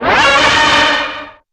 Cosmic Rage / general / combat / ENEMY
caulthit3.wav